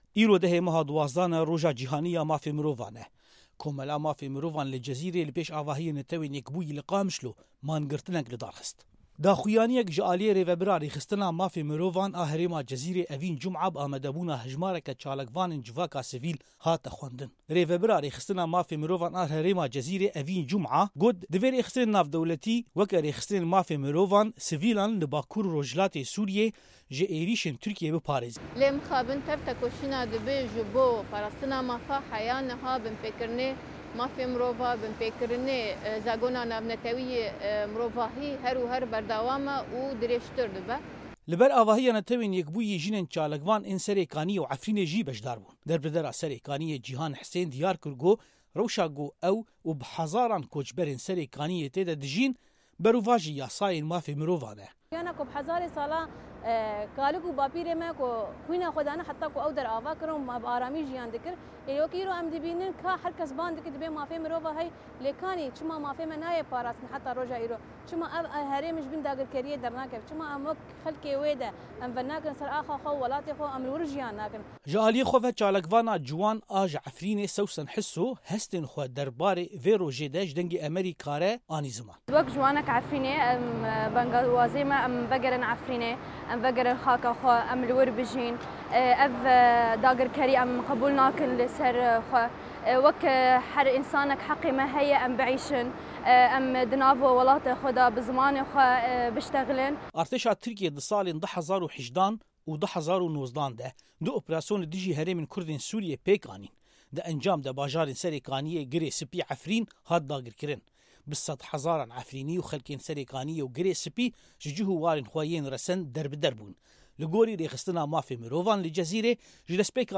Komeleya Mafên Mirovan li Cizîrê, îro 10 Kanûnê di Roja Cîhanî ya Mafên Mirovan de, li pêş avahiya Neteweyên Yekbûyî li Qamişlo mangirtinek lidar xist.
Li ber avahiya Neteweyên Yekbûyî jinên çalakvan ên Serêkaniyê û Efrînê jî beşdar bûn.